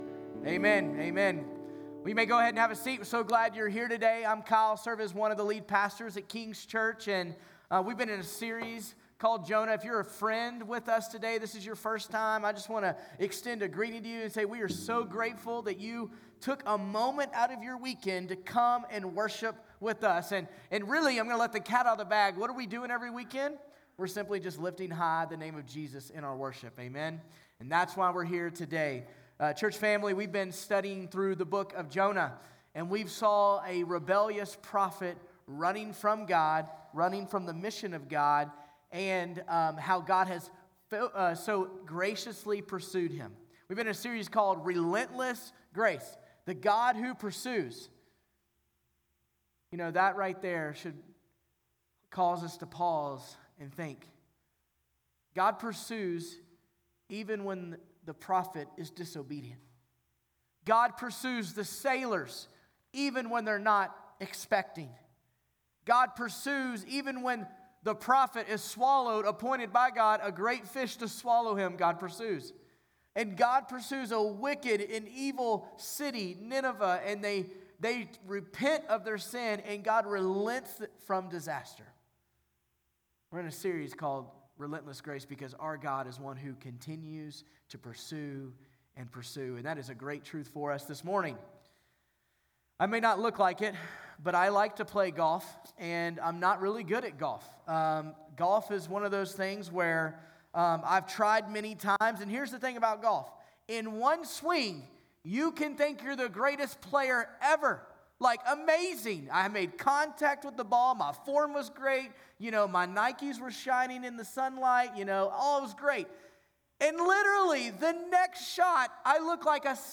August24Sermon.mp3